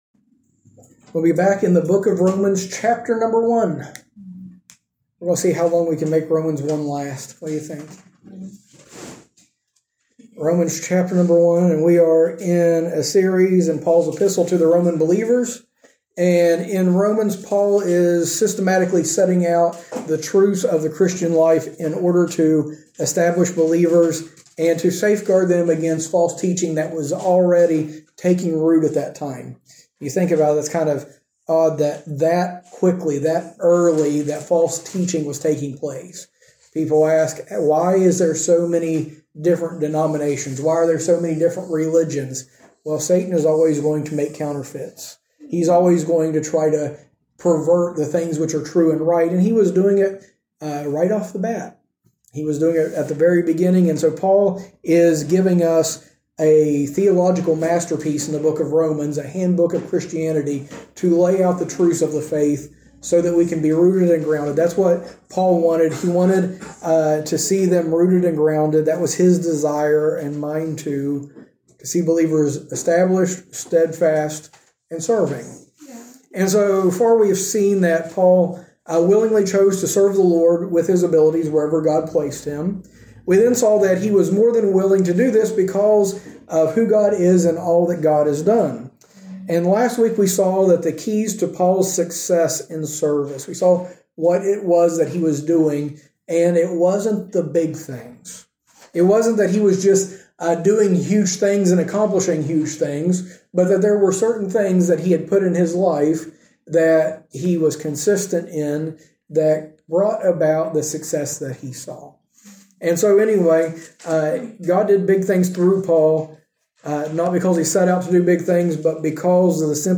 In this sermon series, we go through Paul's letter to the Romans section by section as he shows how the Christian life should be.